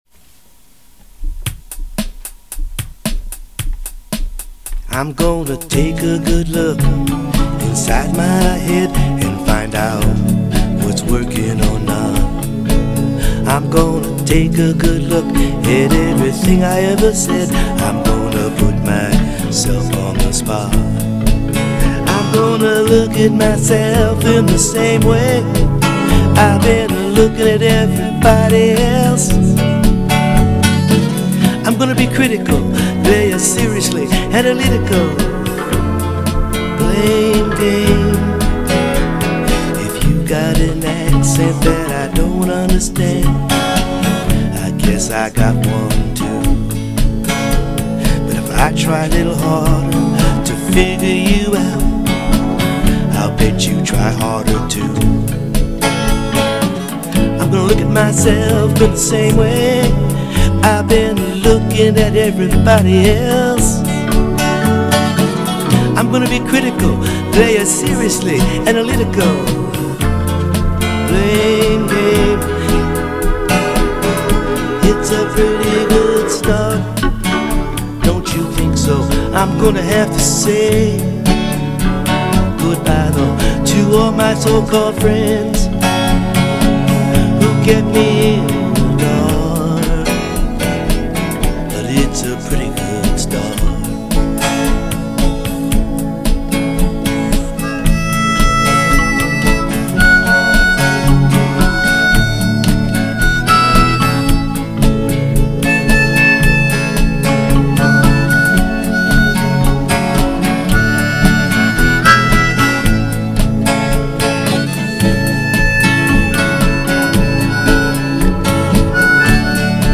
Holiday Music